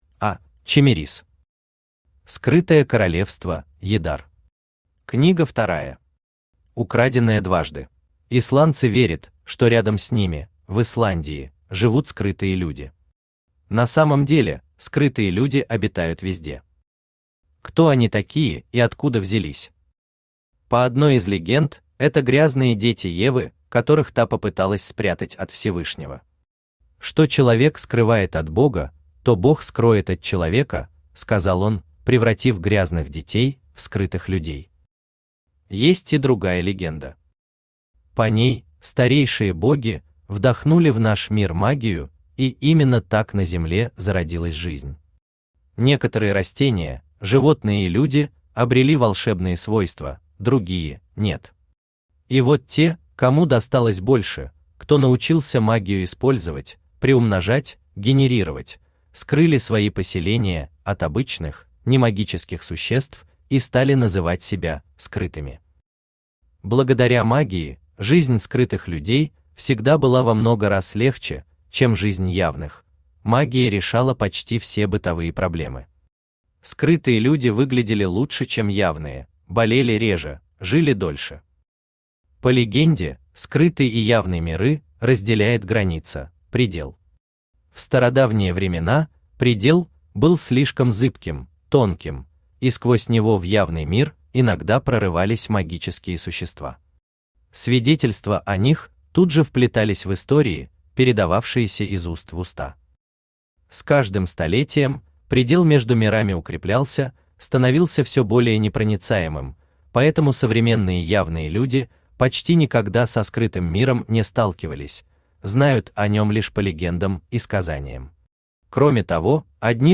Аудиокнига Скрытое королевство Ядар. Книга вторая. Украденное дважды | Библиотека аудиокниг
Украденное дважды Автор А.Чемерис Читает аудиокнигу Авточтец ЛитРес.